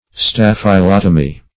Search Result for " staphylotomy" : The Collaborative International Dictionary of English v.0.48: Staphylotomy \Staph`y*lot"o*my\ (-l[o^]t"[-o]*m[y^]), n. [Gr.
staphylotomy.mp3